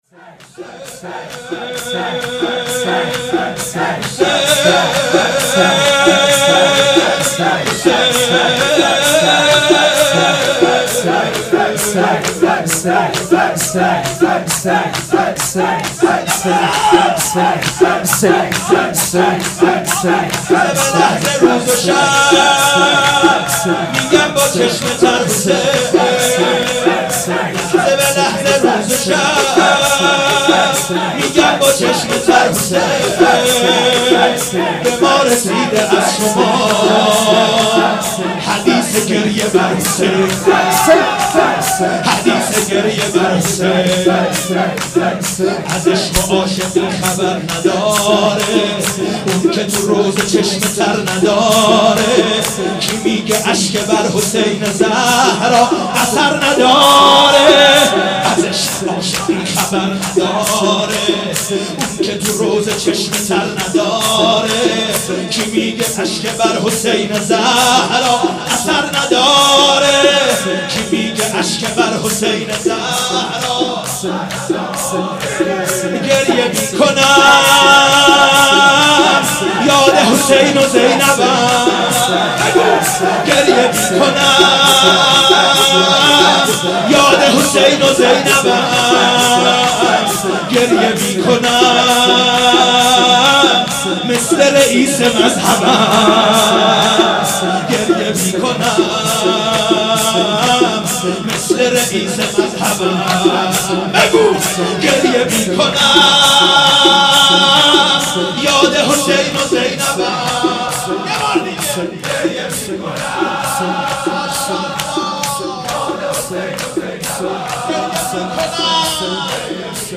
هیات ال یاسین قم
روضه امام صادق علیه السلام